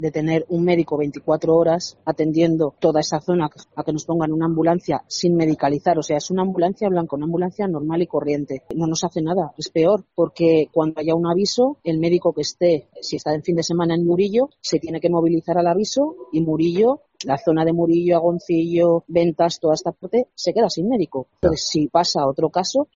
Encarna Fuertes, alcaldesa de Agoncillo